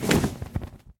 Windows64Media / Sound / Minecraft / mob / enderdragon / wings4.ogg
wings4.ogg